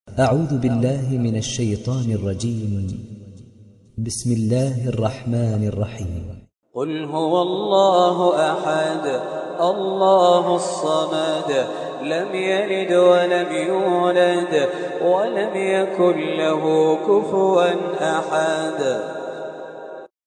دانلود سوره الإخلاص mp3 خالد الجليل (روایت حفص)